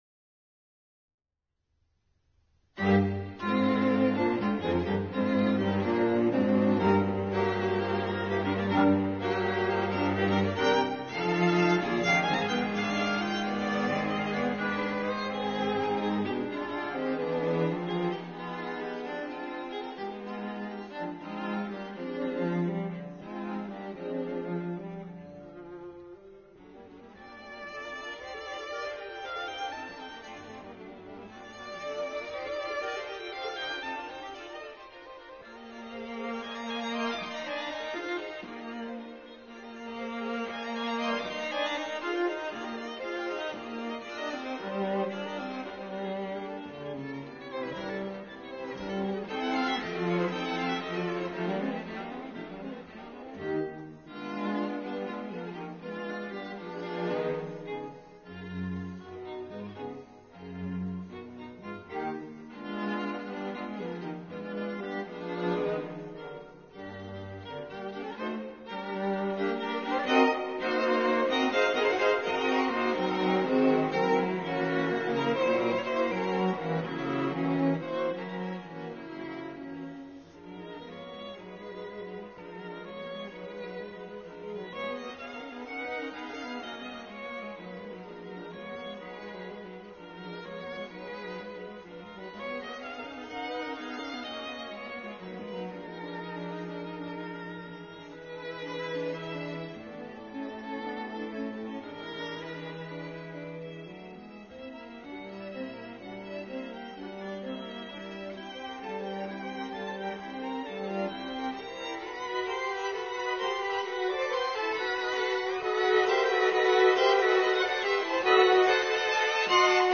Quarteto de cordas